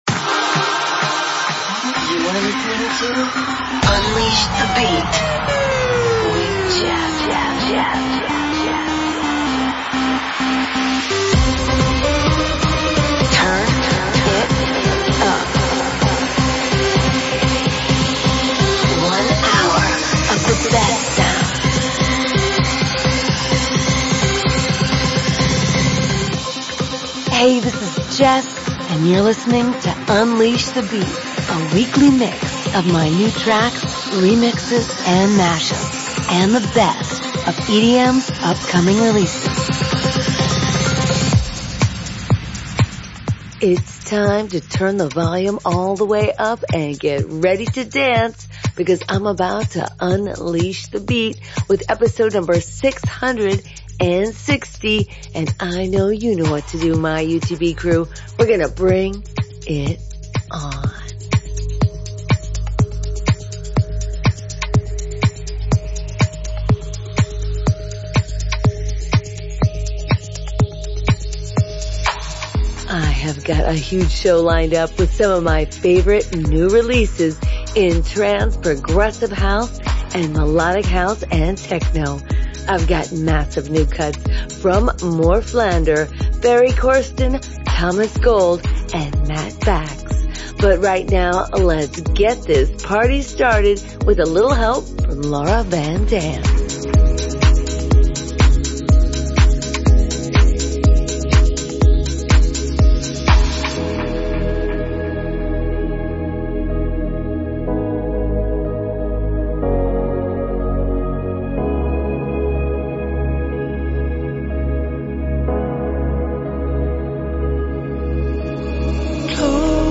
Trance-Melodic House